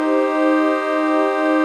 CHRDPAD098-LR.wav